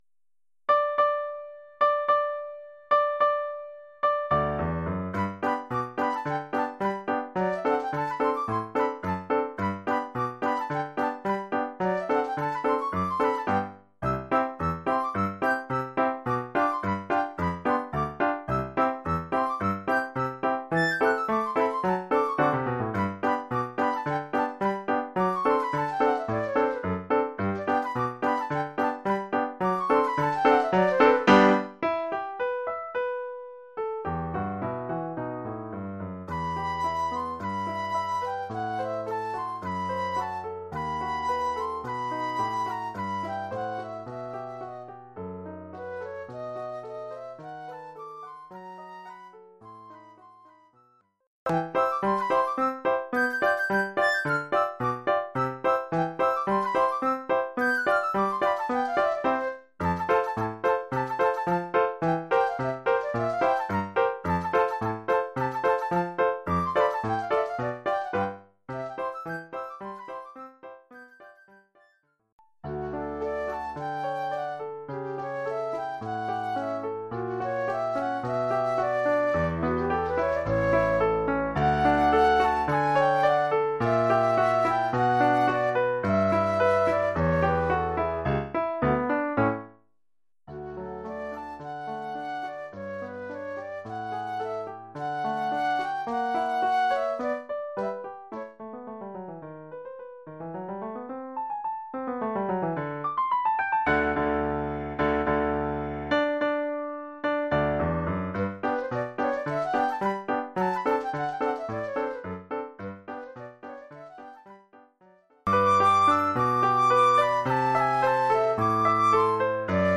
Formule instrumentale : Flûte et piano
Oeuvre pour flûte avec
accompagnement de piano.